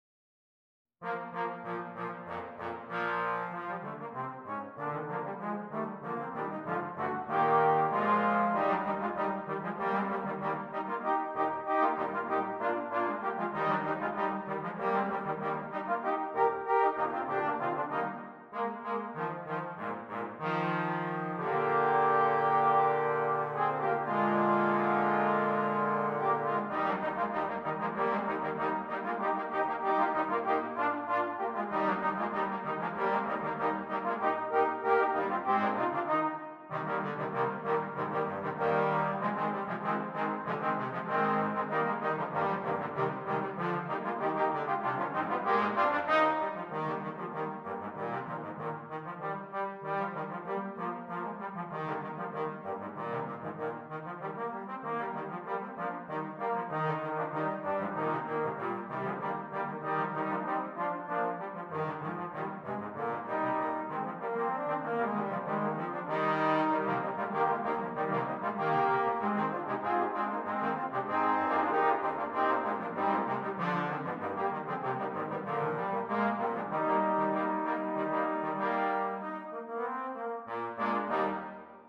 4 Trombones
Traditional